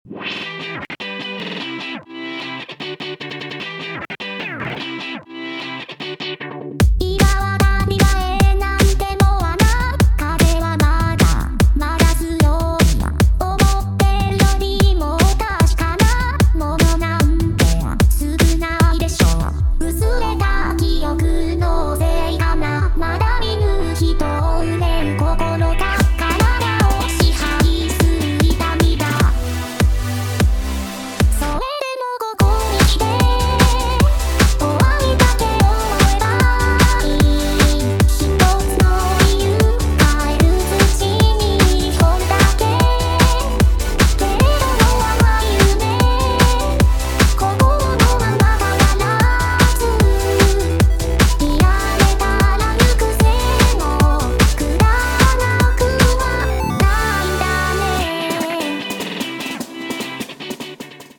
Vocaloid